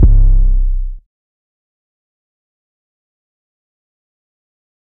yikes 808.wav